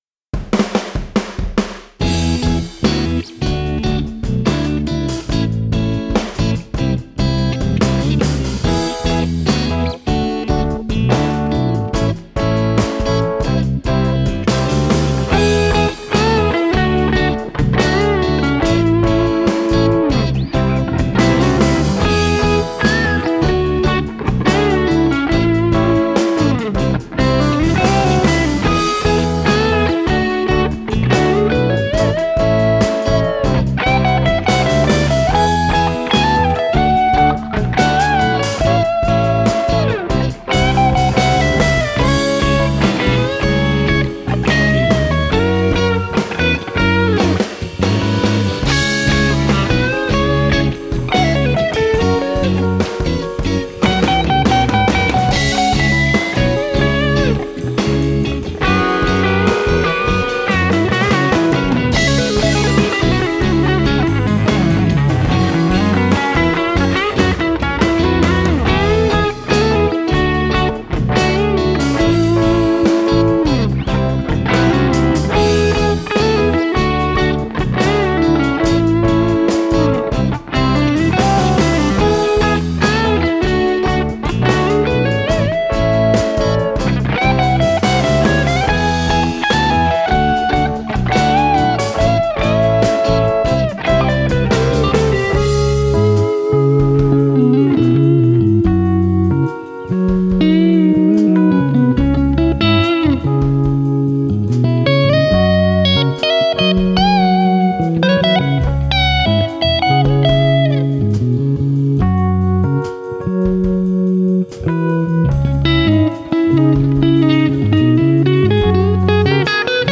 Guitars, bass, keyboards and drums all played by myself.
• Electric Guitar: Fender Stratocaster.
• Bass Guitar: Sterling StingRay.
• Keyboards: Nord Stage 2.
• Drums: Roland V-Drums triggering the Addictive Drums plug-in, by XLN Audio.
• All effects plug-ins (guitar tones, reverbs, compressors etc.) by Blue Cat Audio.